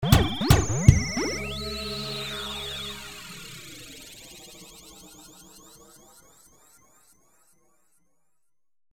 In questo boxxetto qua di fianco.. troveremo dei loopz fatti da me con un programmino spettacolare... che si chiama Fruity Loops versione 3.4